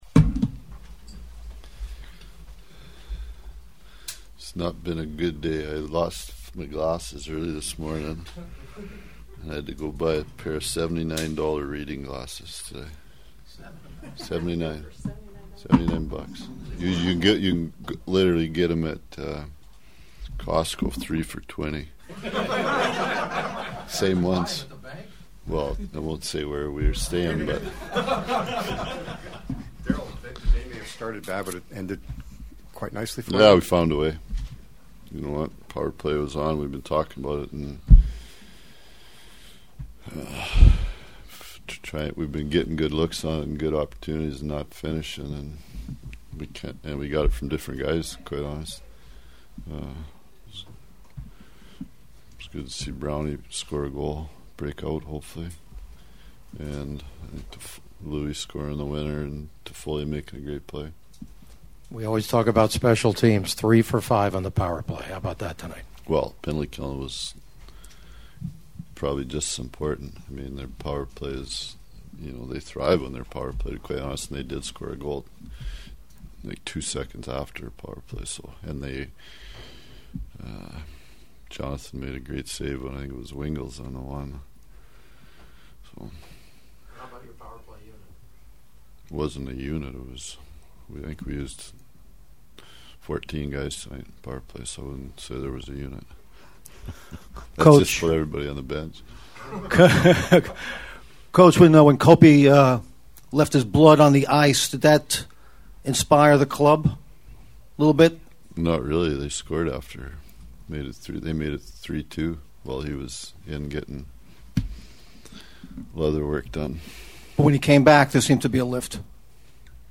The sounds of the game from the locker room tell a story of a team that’s feeling relieved as much as joy after one of the great playoff wins in their club’s history.
Kings coach Darryl Sutter who started out with a stand-up routine about having to buy expensive reading glasses before this whole night unfolded: